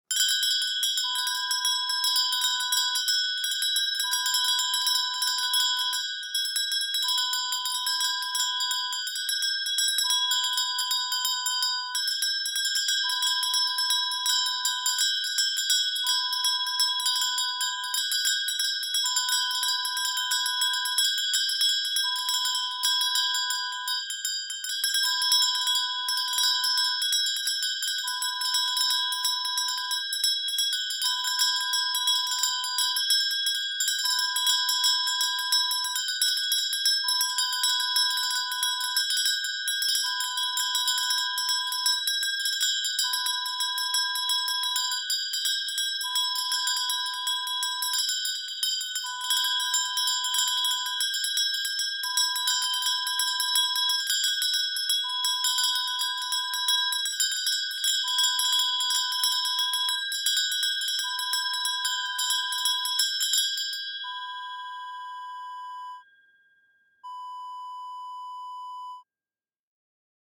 Bells Ringing Wav Sound Effect
Description: The sound of two bells ringing
Properties: 48.000 kHz 24-bit Stereo
A beep sound is embedded in the audio preview file but it is not present in the high resolution downloadable wav file.
Keywords: bells, ring, ringing, ringer, shake, christmas, horse bell, brass, chime, chiming
bells-ring-preview-1.mp3